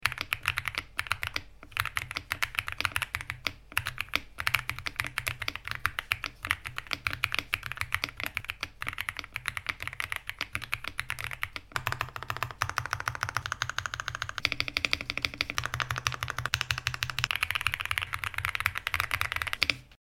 lingbao MK75 Sound Test sound effects free download